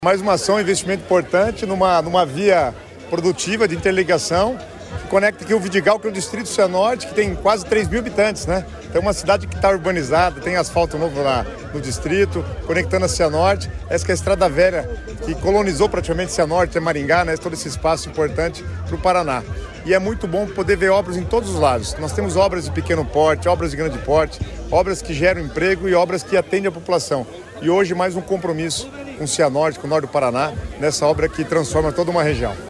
Sonora do secretário das Cidades, Guto Silva, sobre a pavimentação que liga distrito de Cianorte a Jussara